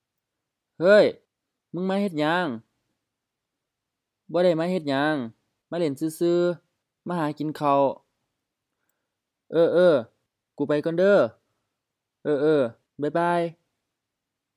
BCF01 Greetings and leave takings (informal) — Dialogue A